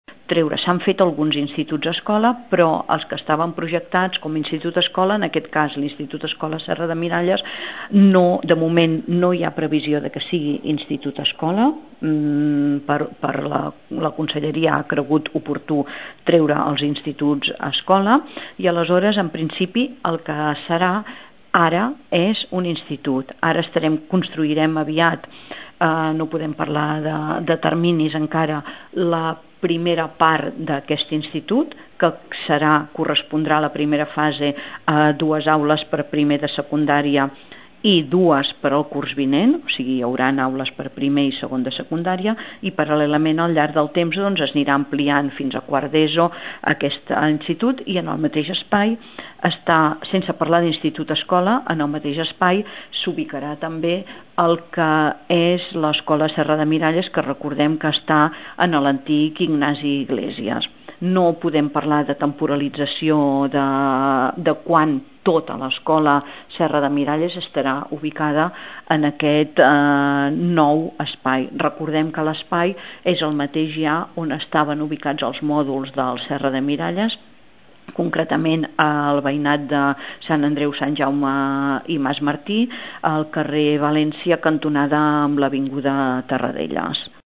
La Generalitat ha canviat el criteri inicial i, finalment, l’Escola Serra de Miralles no serà escola-institut, tal i com s’havia previst des del principi.  Ho explica la regidora d’ensenyament, Maria Àngels Cayró,